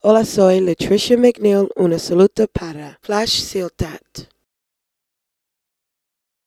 Salutació de la cantant nord-americana, Lutricia McNeal.
FM